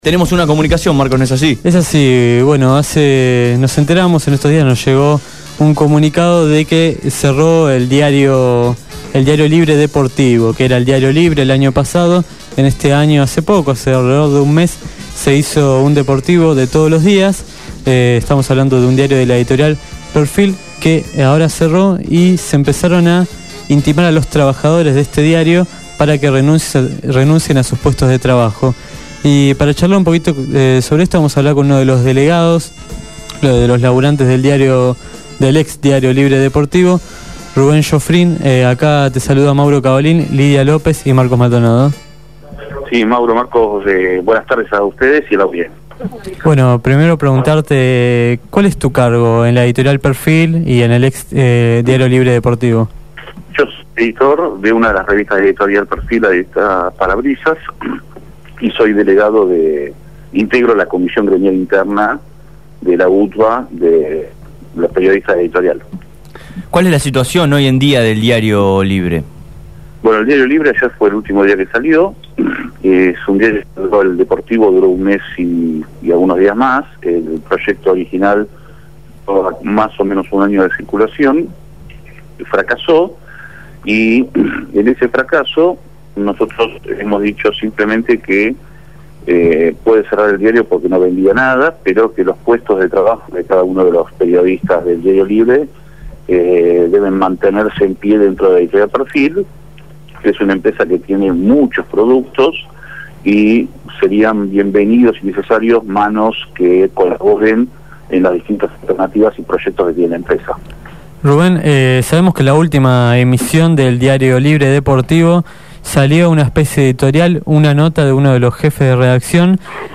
Cierre del diario «Libre deportivo» | Radio Grafica FM 89.3